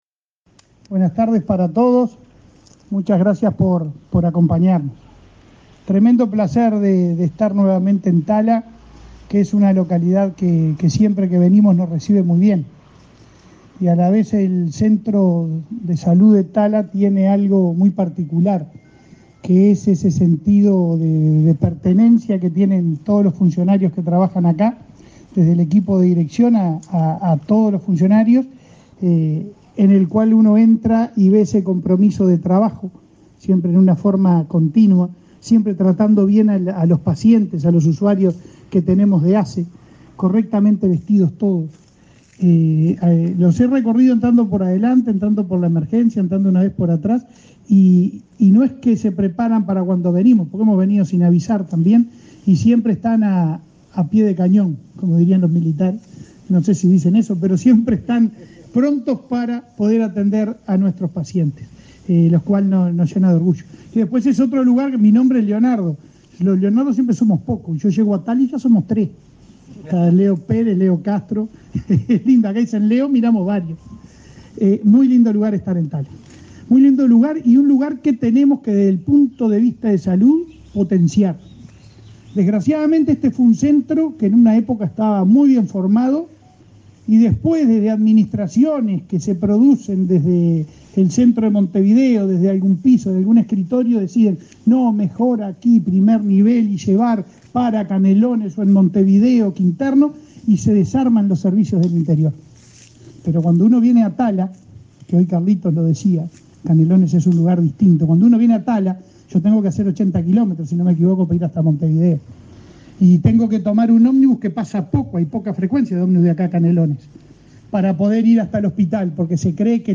Palabras del presidente de ASSE, Leonardo Cipriani
ASSE entregó ambulancia y minibús para Centro Auxiliar de Tala, Canelones. El presidente del organismo, Leonardo Cipriani, participó en la ceremonia.